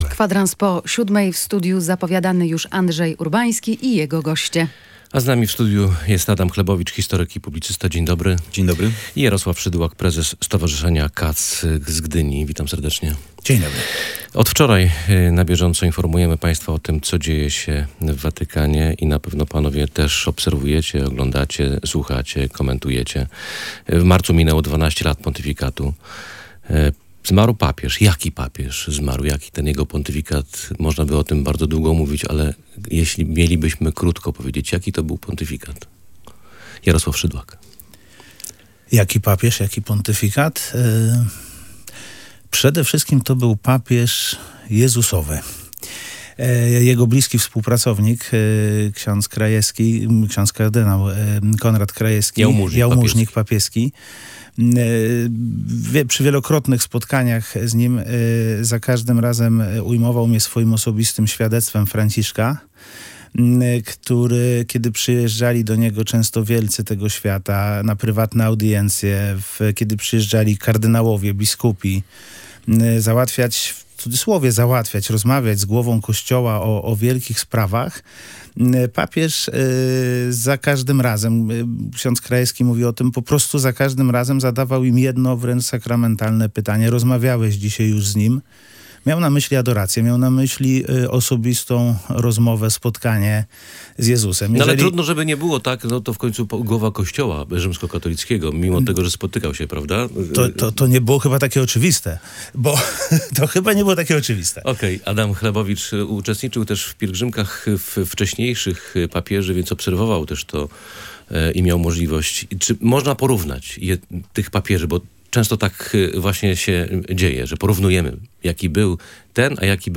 Papież Franciszek kierował swoje przesłanie do każdego człowieka, także do ludzi poszukujących wiary. Z tego pontyfikat zapamiętają goście Radia Gdańsk: